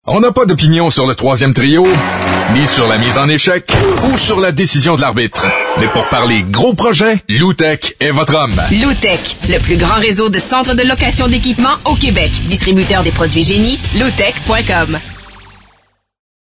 Nouvelles publicités radiophoniques
Nous vous invitons à écouter nos campagnes publicitaires radiophoniques sur les ondes de 98,5 fm, des stations NRJ à travers le Québec et aussi sur TSN 690.